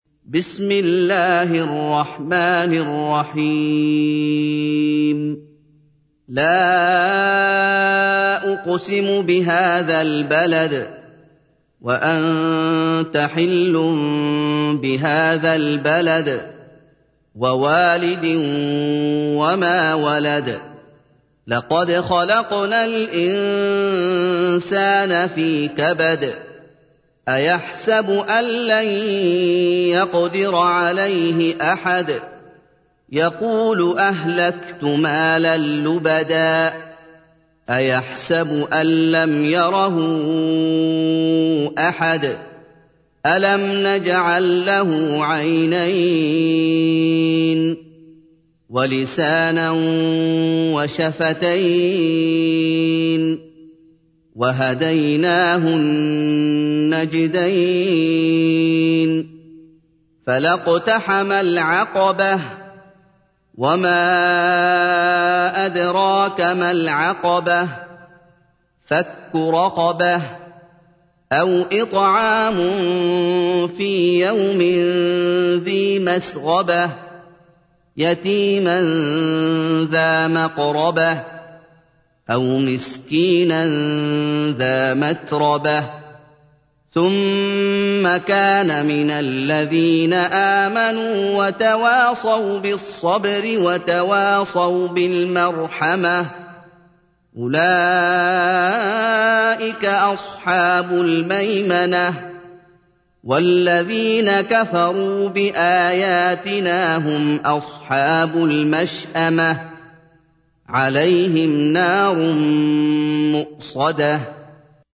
تحميل : 90. سورة البلد / القارئ محمد أيوب / القرآن الكريم / موقع يا حسين